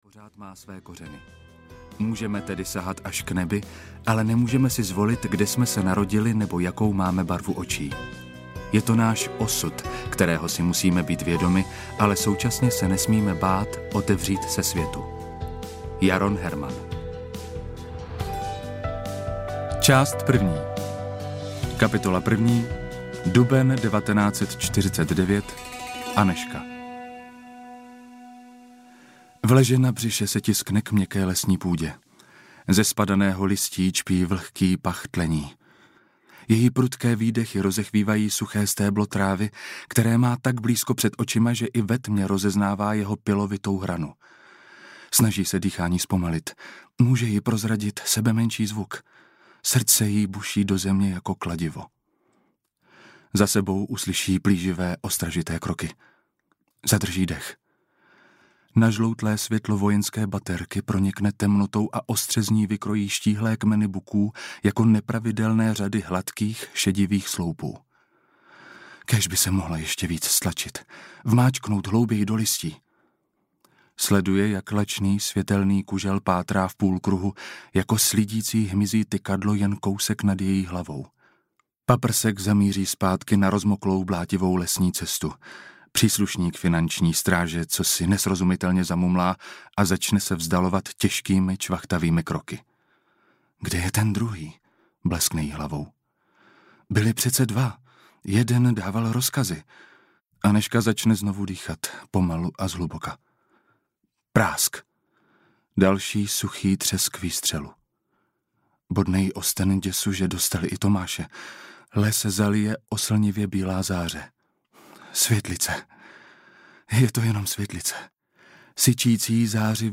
Neviditelné kořeny audiokniha
Ukázka z knihy